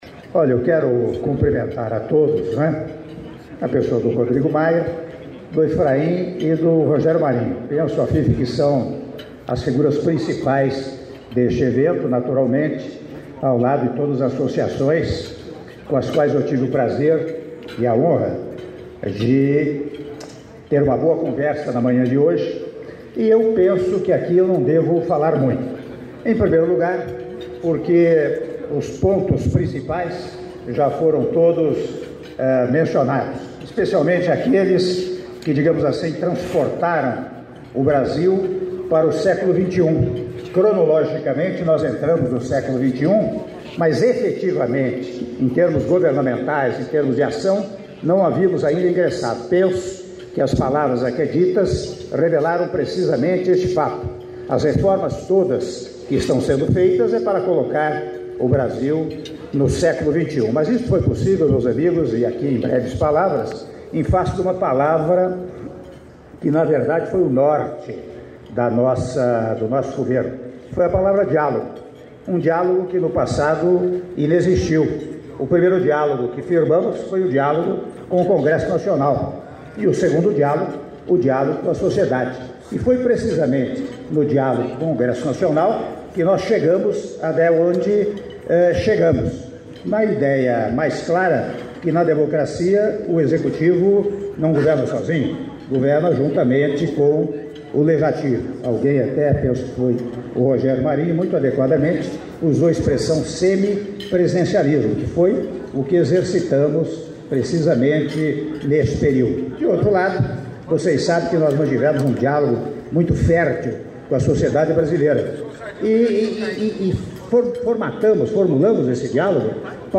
Áudio do discurso do Presidente da República, Michel Temer, durante almoço da Frente Parlamentar Mista de Comércio Serviços e Empreendedorismo - (04min43s) - Brasília/DF — Biblioteca